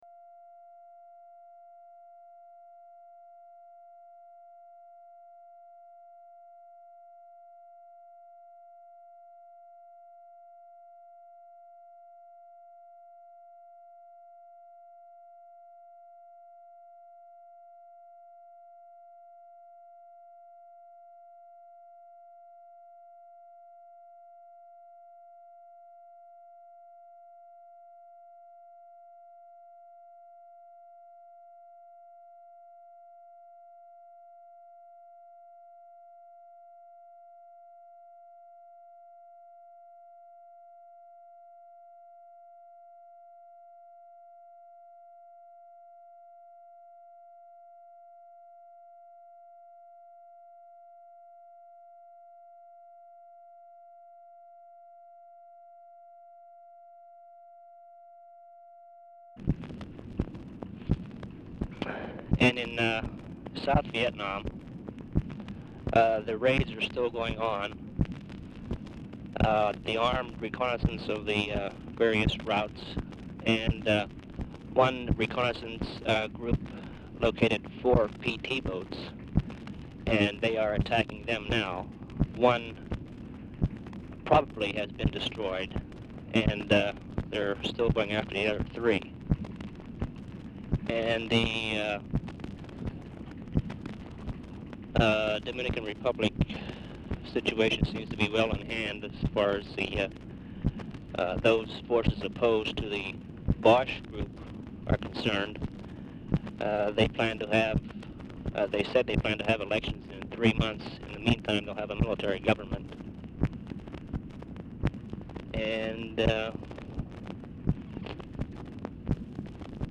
Telephone conversation # 7371, sound recording, LBJ and WH SITUATION ROOM, 4/28/1965, 3:30AM | Discover LBJ
RECORDING STARTS AFTER CONVERSATION HAS BEGUN
Format Dictation belt
Location Of Speaker 1 Mansion, White House, Washington, DC
Specific Item Type Telephone conversation